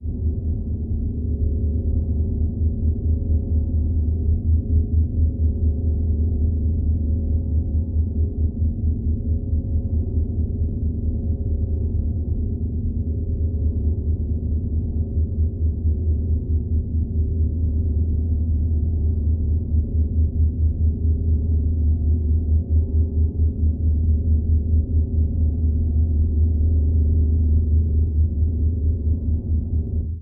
The following audio examples consist of processed, transposed and superimposed tubax sounds amplifying tonal interferences.
Audio Example: Mixture
04_Drone_D_Dis_E.mp3